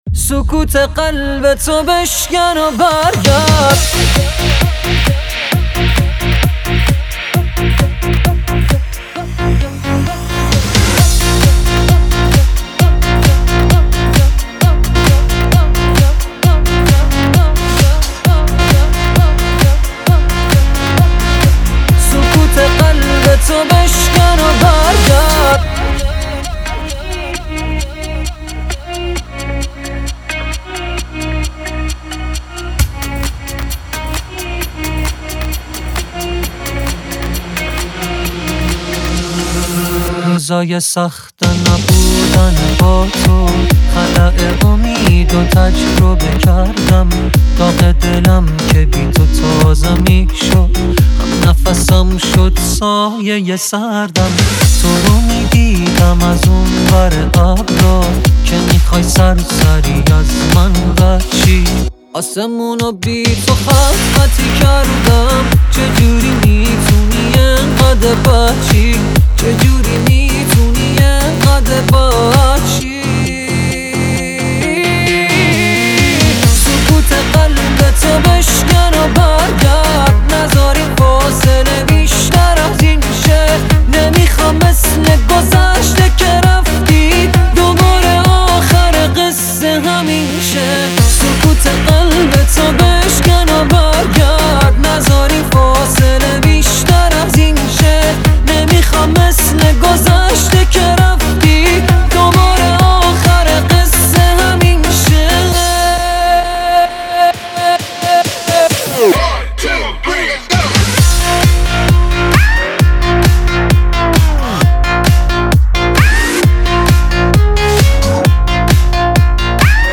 ریمیکس بندری
ریمیکس تریبال